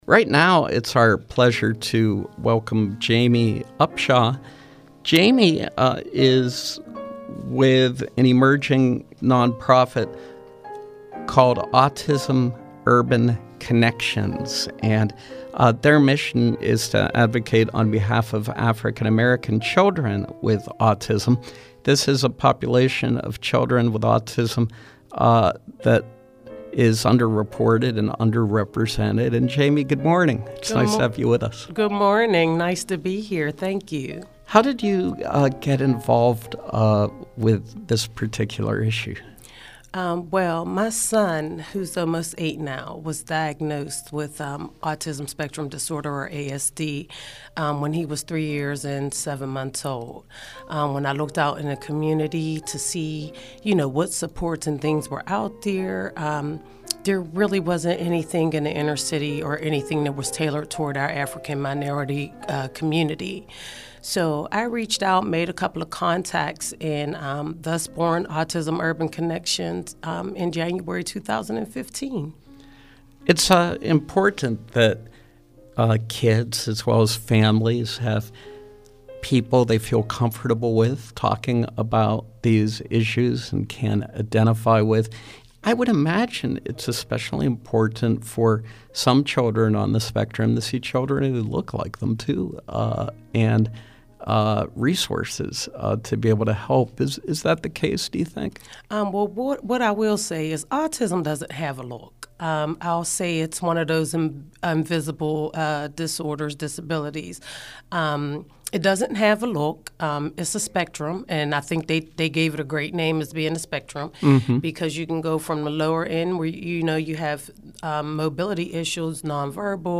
In Studio Pop-Up: Autism Urban Connections
Interviews